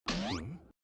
Вы можете слушать онлайн и скачивать различные вопросительные интонации, загадочные мелодии и звуковые эффекты, создающие атмосферу тайны.